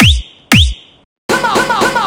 117 BPM Beat Loops Download